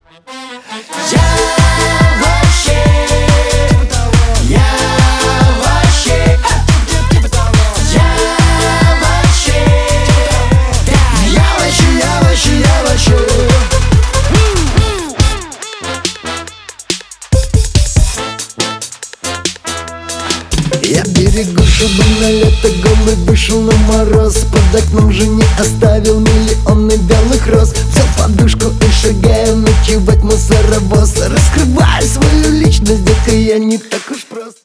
Поп Музыка
клубные